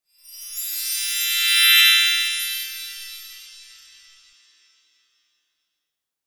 Shiny Sparkling Transition Sound Effect
Description: Shiny sparkling transition sound effect.
Add instant sparkle and excitement to your projects with this festive sound.
Shiny-sparkling-transition-sound-effect.mp3